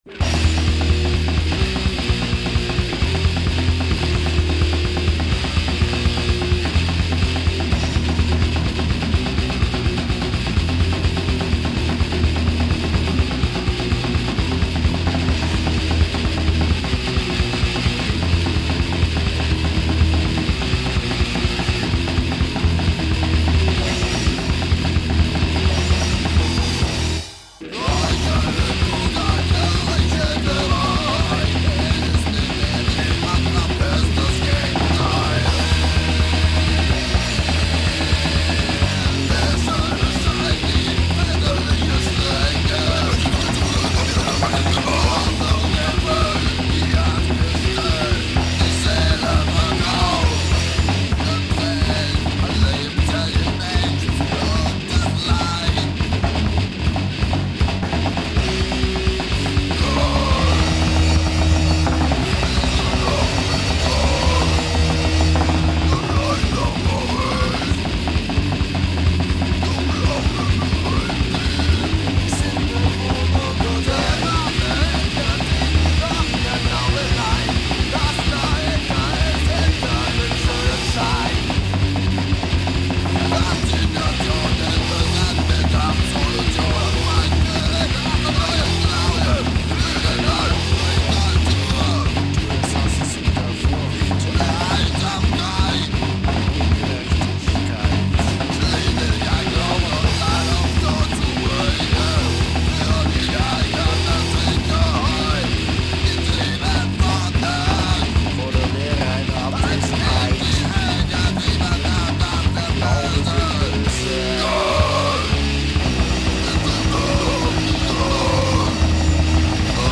Alle tracks sind remastert und kostenlos downloadbar